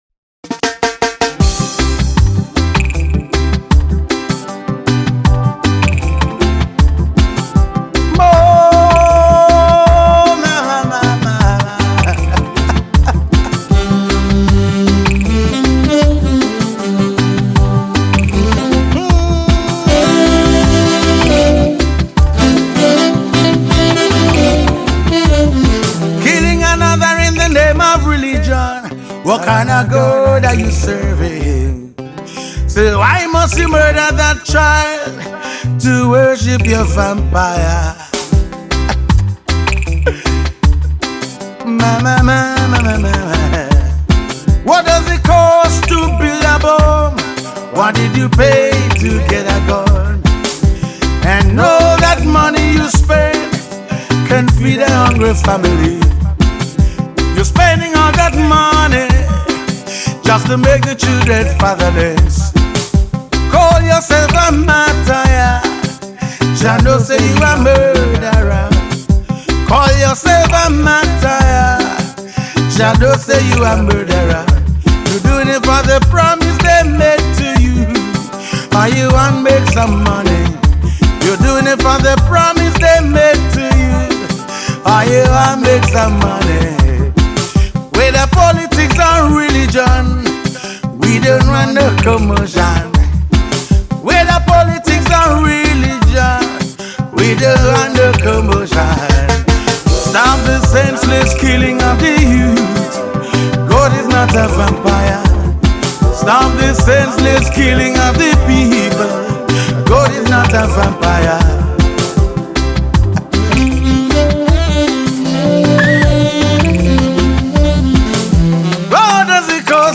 Gospel
poet spoken words and reggae gospel artist.